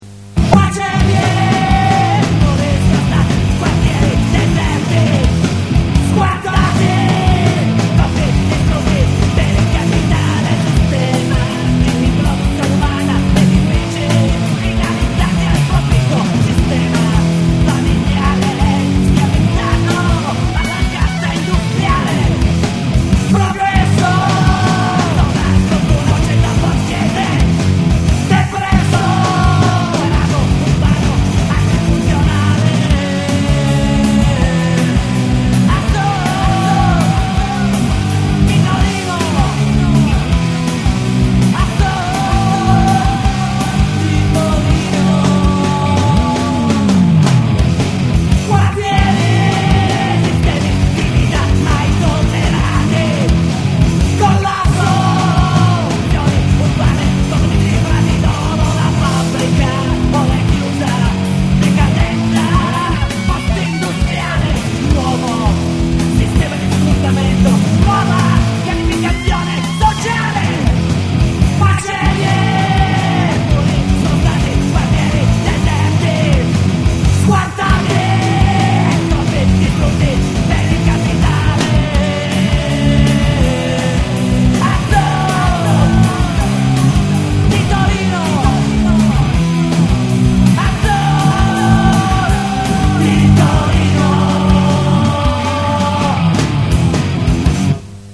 1997, Post punk, Torino)